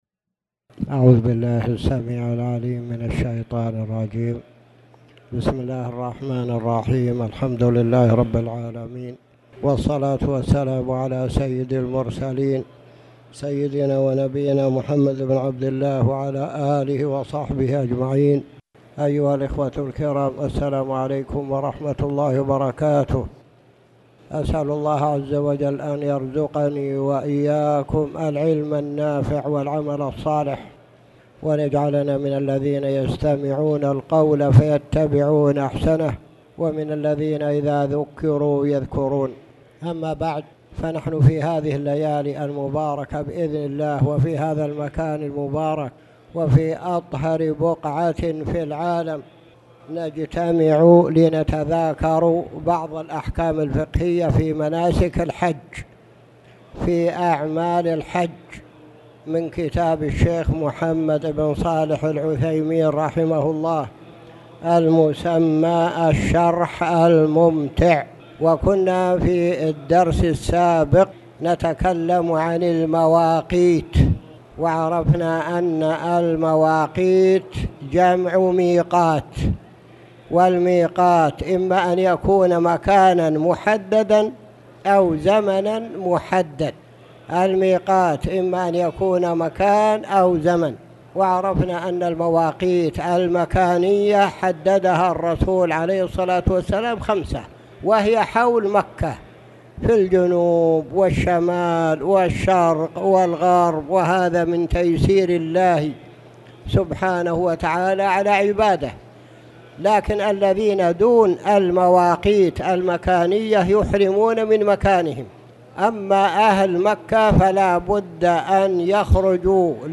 تاريخ النشر ٩ ذو القعدة ١٤٣٨ هـ المكان: المسجد الحرام الشيخ